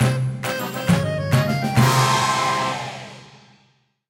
hindu_circus_loading_01.ogg